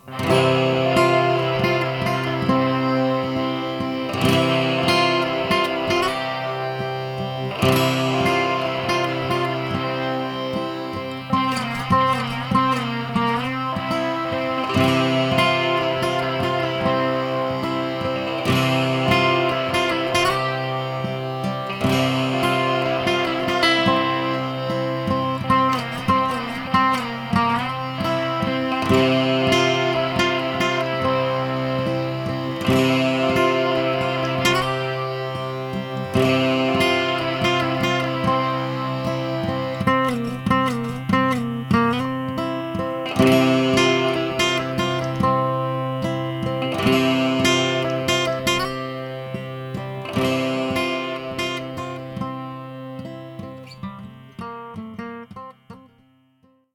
‘Frank’ is a continuation of the C Major tuned guitar idea that I had before I took my trip to the States in October.
Today presented a few recording challenges (as always) because I haven’t got a pair of headphones and had to find levels and mix the master using in-ear earphones and that’s never a good thing!
The demo is guitars only, and I used the following on the track:
• Cort Acoustic guitar for the main pick guitar and rhythm accompaniment
• Danelectro guitar using my mini Orange amp with a bit of distortion on it for a high end chord/drone sound (the mini Orange amp is amazing!)
• Danelectro with the Electro Harmonix sitar pedal